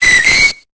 Cri de Rémoraid dans Pokémon Épée et Bouclier.